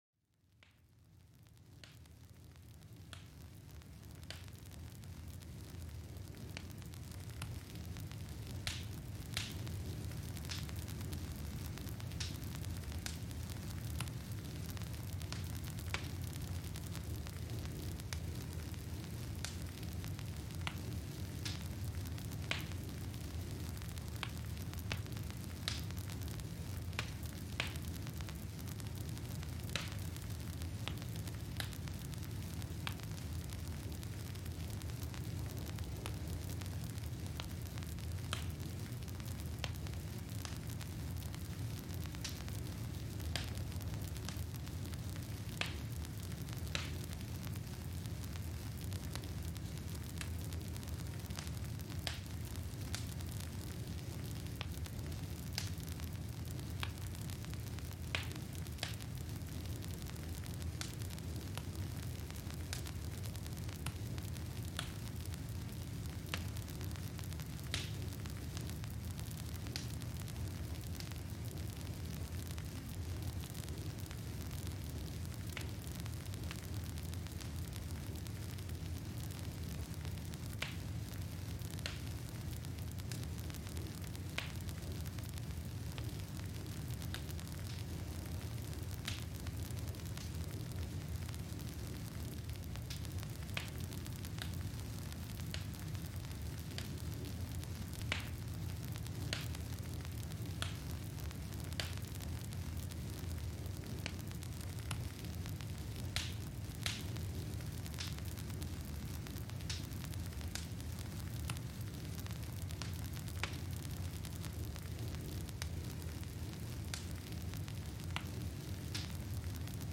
Feu de Camp Relaxant : Plénitude et Sérénité